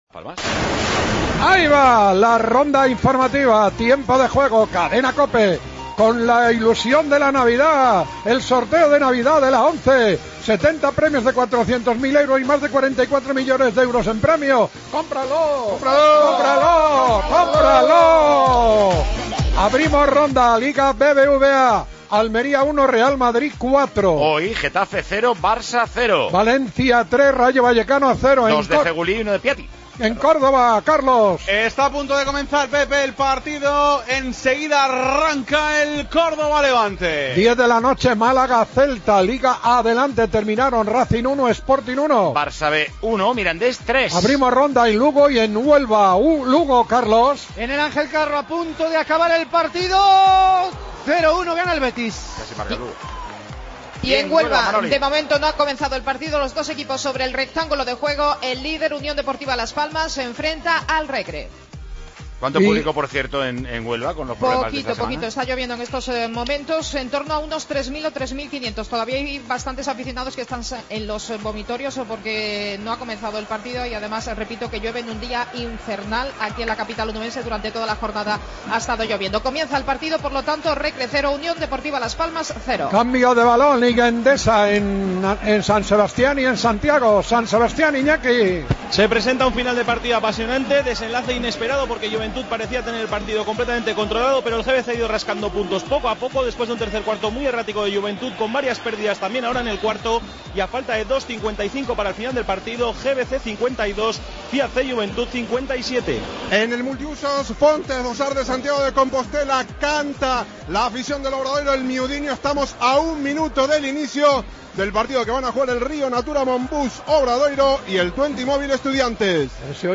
Redacción digital Madrid - Publicado el 13 dic 2014, 20:39 - Actualizado 14 mar 2023, 00:56 1 min lectura Descargar Facebook Twitter Whatsapp Telegram Enviar por email Copiar enlace Escucha la primera parte del Córdoba-Levante y al extécnico italiano Arrigo Sacchi. En juego, la jornada 17 de la Liga Adelante y la jornada 11 de la Liga Endesa.